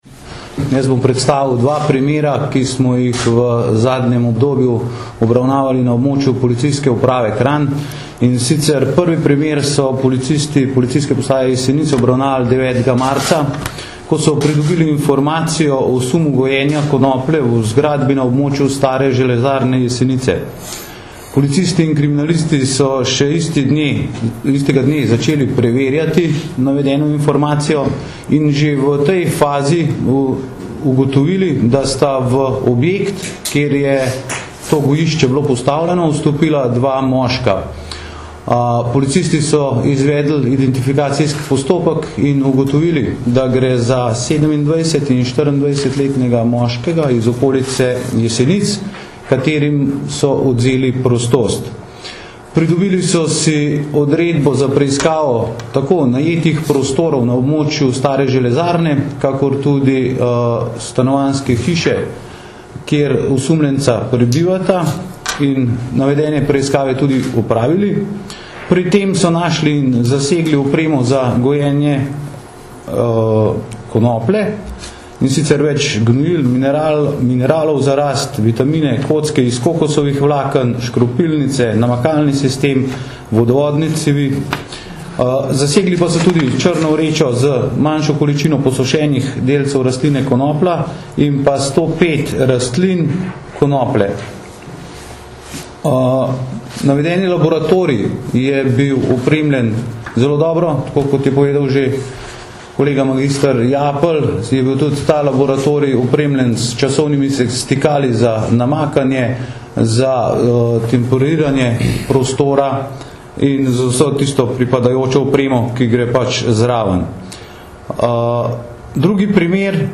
Na današnji novinarski konferenci smo podrobneje predstavili sedem najnovejših uspešno zaključenih kriminalističnih preiskav na področju prepovedanih drog ter spregovorili o problematiki njihovega gojenja in uživanja.
Zvočni posnetek izjave